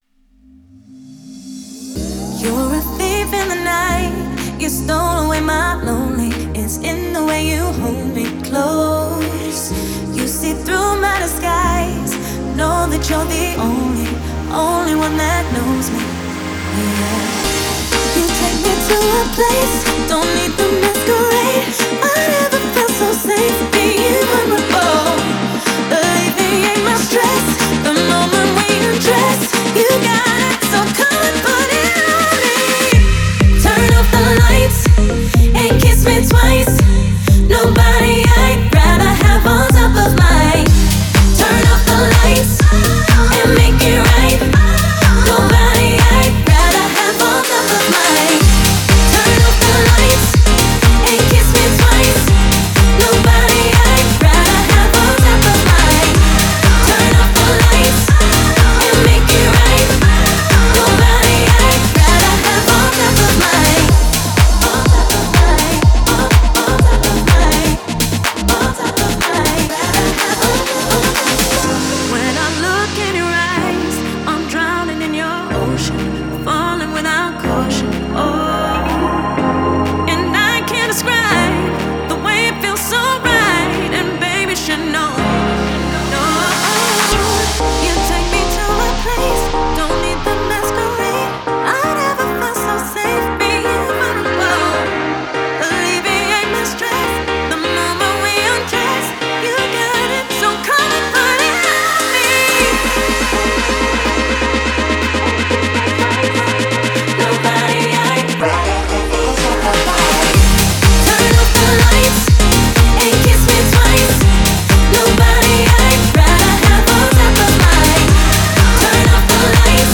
динамичная EDM-композиция